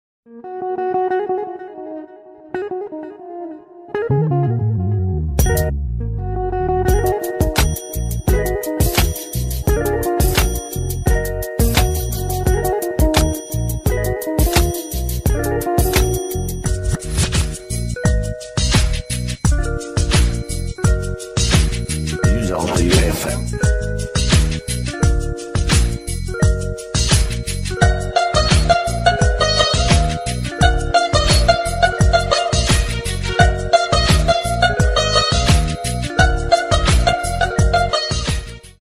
Рингтоны Без Слов
Рингтоны Ремиксы
Танцевальные Рингтоны